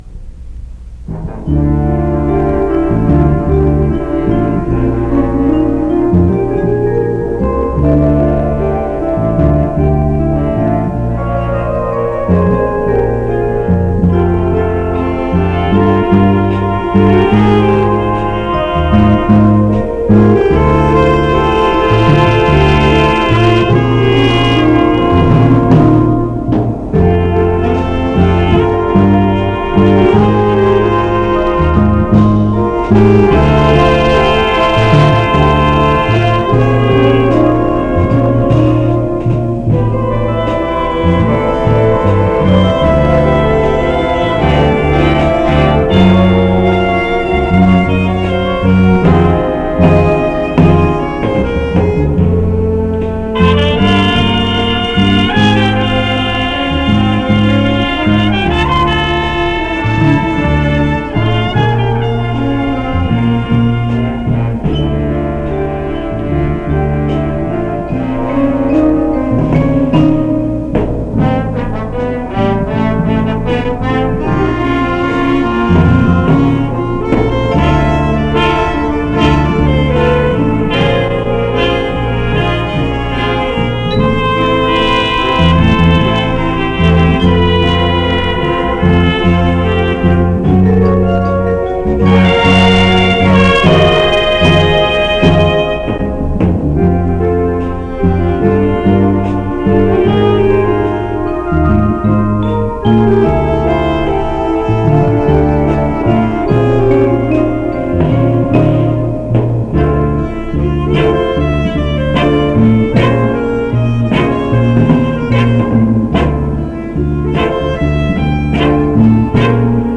washington high school: 1969 summer jazz band
electric bass
percussion
cordovox
vibraphone
trumpet
alto sax
... tenor sax
... baritone sax